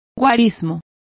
Complete with pronunciation of the translation of numbers.